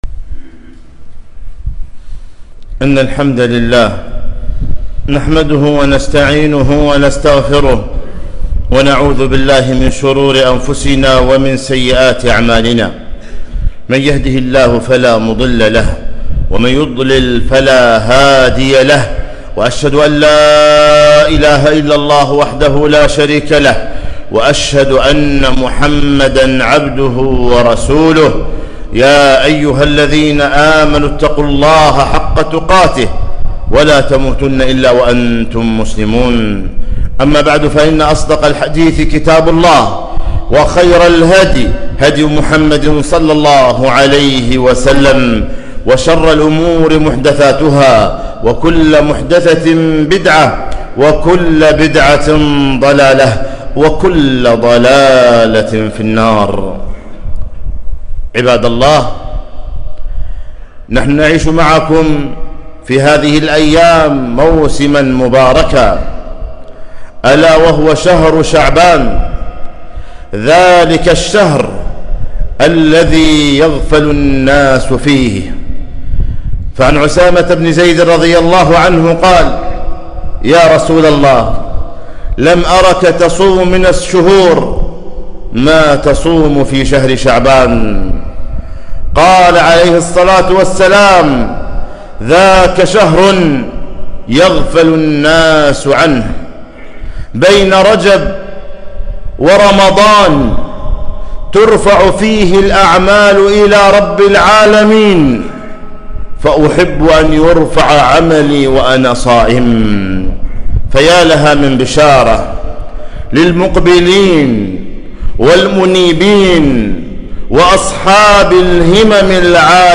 خطبة - شهر شعبان